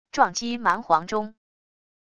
撞击蛮皇钟wav音频